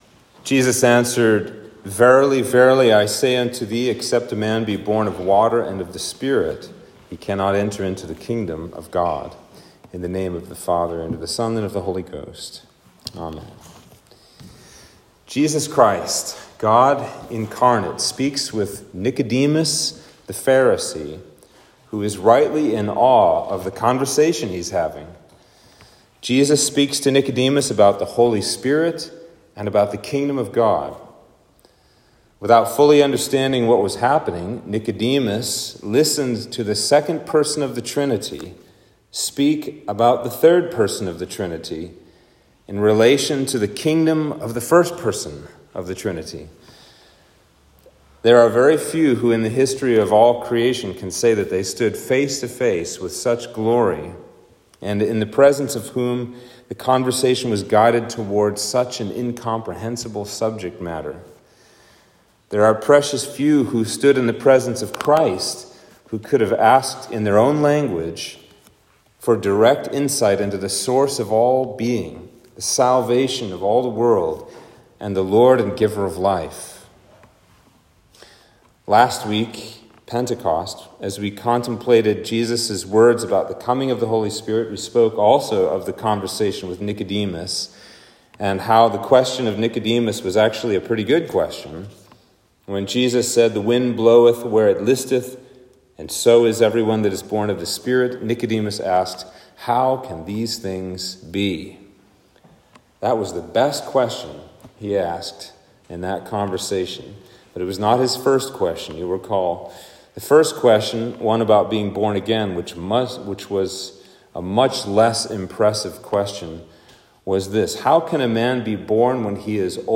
Sermon for Trinity Sunday